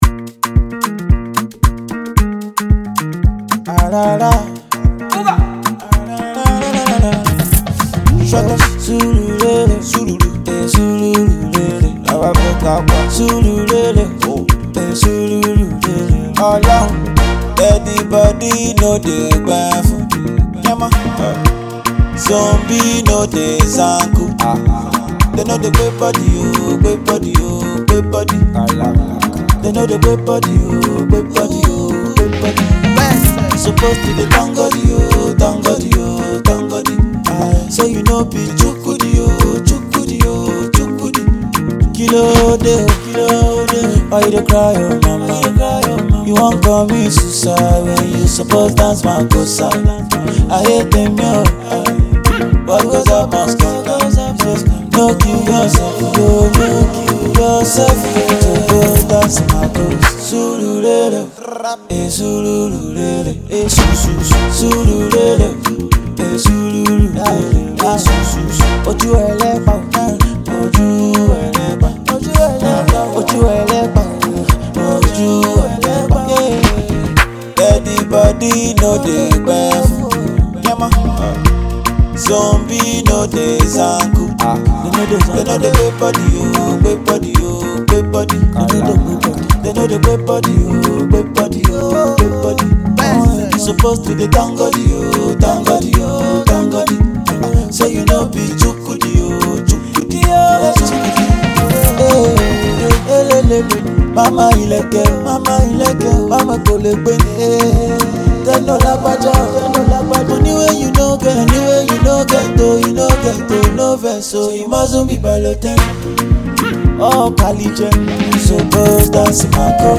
Nigerian singer and songwriter
groovy track